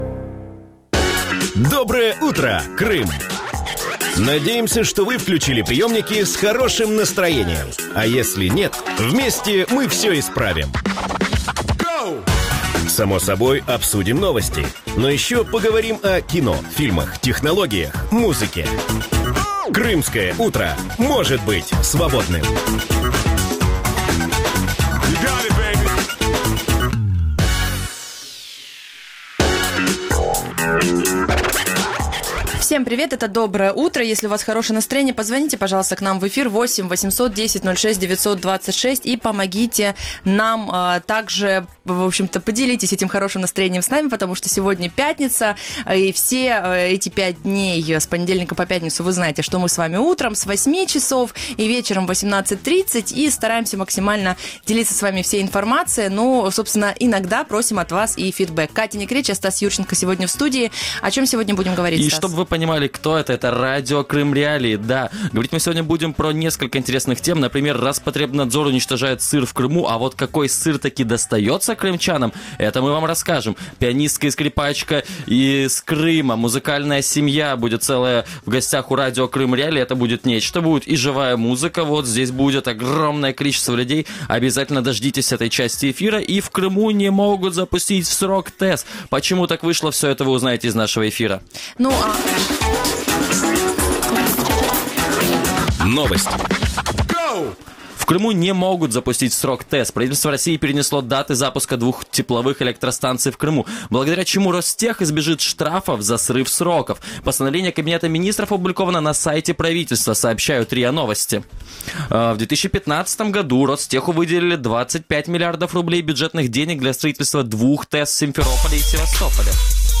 Как звучит скрипка в эфире Радио Крым.Реалии?